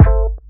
Banks BD.wav